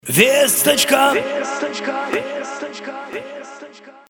• Качество: 256, Stereo
русский шансон
голосовые